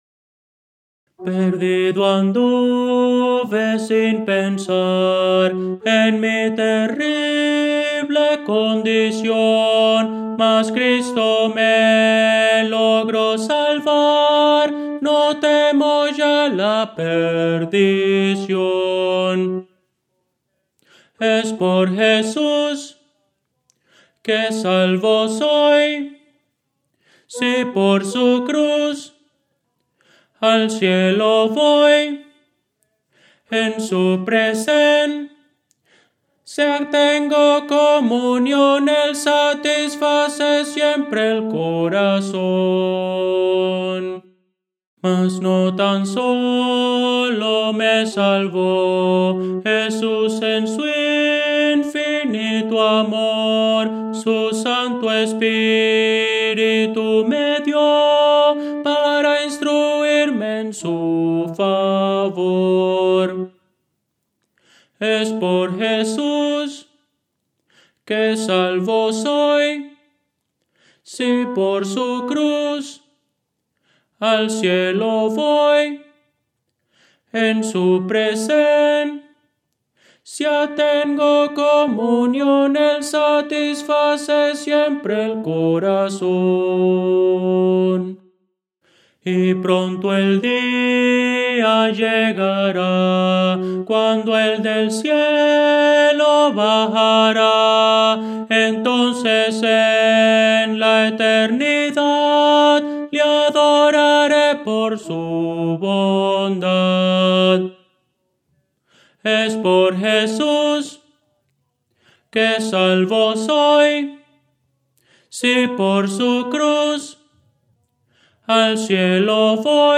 A capela – 4 Voces
Voces para coro
Tenor – Descargar